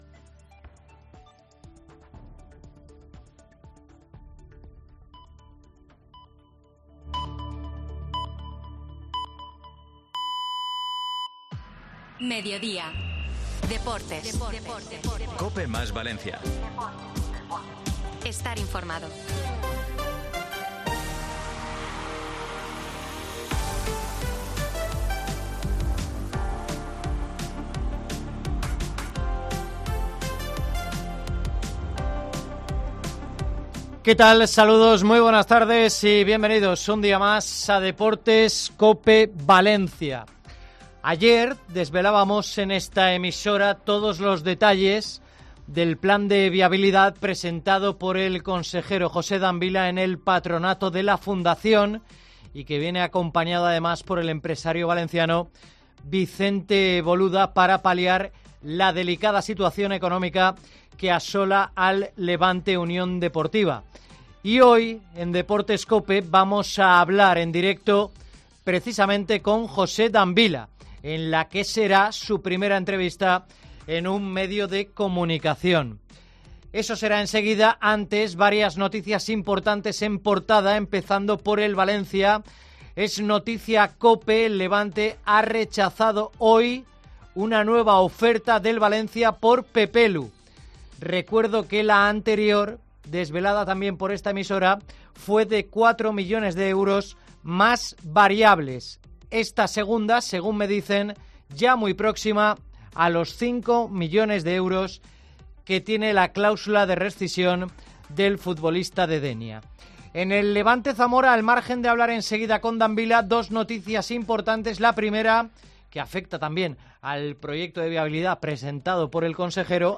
Primera entrevista en un medio de comunicación